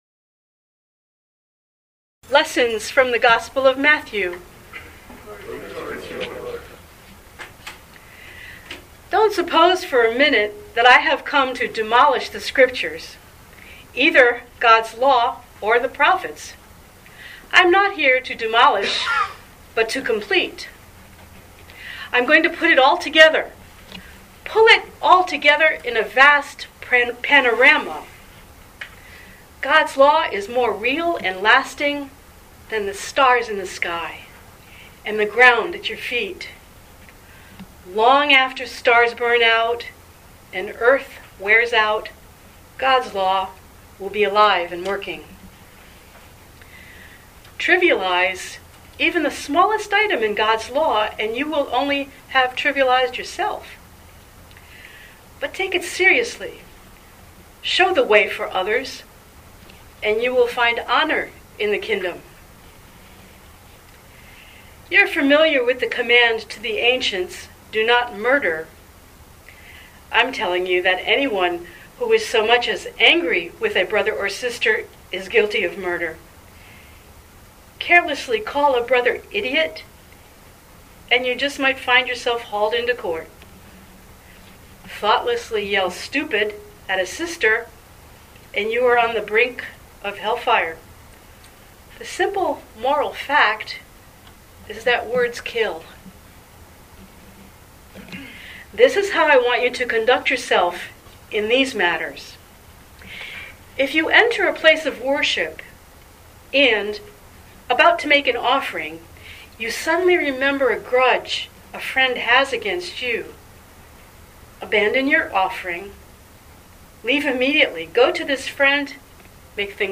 Living Beatitudes Community Homilies: Integrity